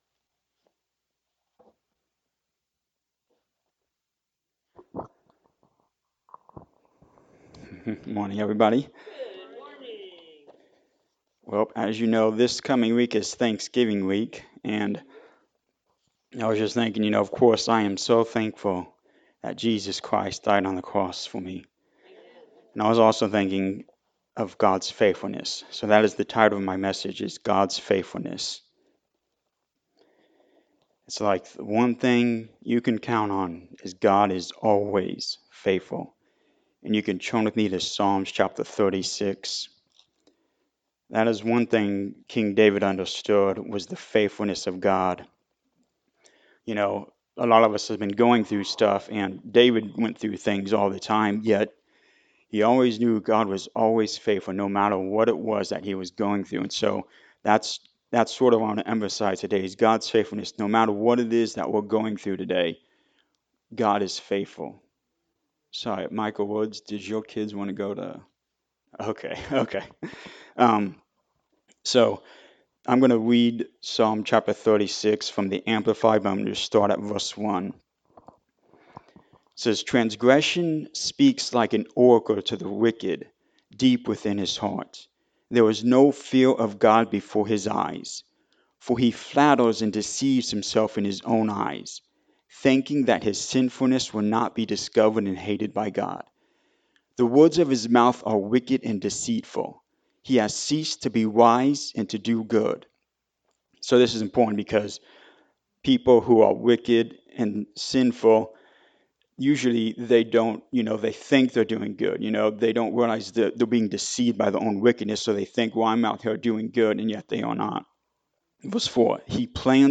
Psalm 36:1-12 Service Type: Sunday Morning Service As we near Thanksgiving